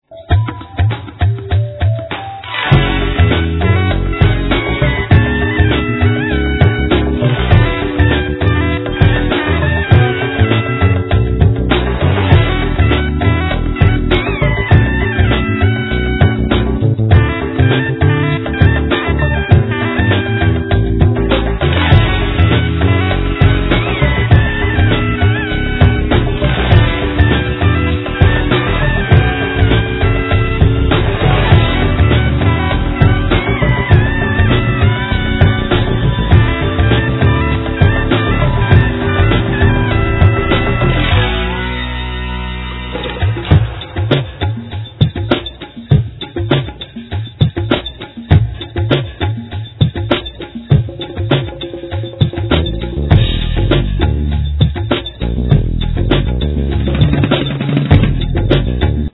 Saxophone, Synthsizer
Otera, Utogardon, Mouth harp